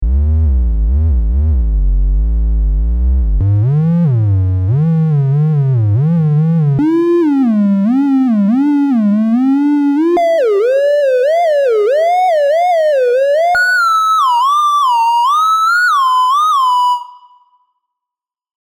Sample and Hold – Interpolated modulating pitch
Tri-SaH-int-16th_-3dB.mp3